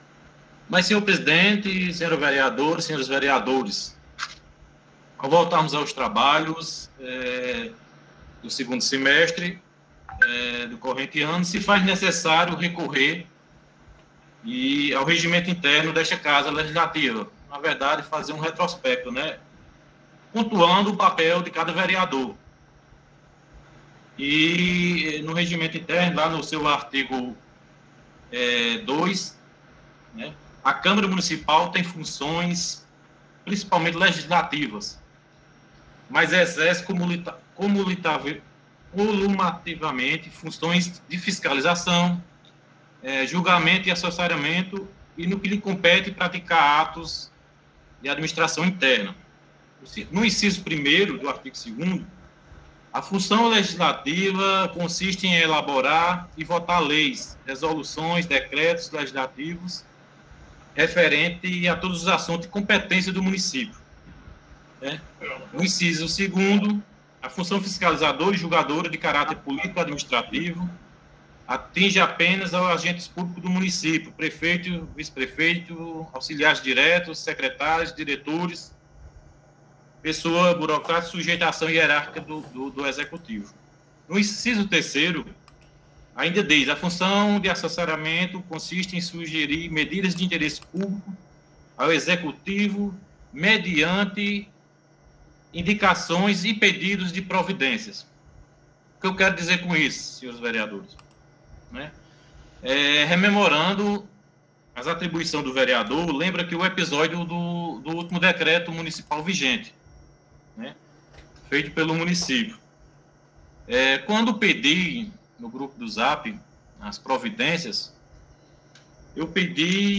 A sessão ordinária foi realizada de forma remota e transmitida pela Rádio Conexão (104,9 FM).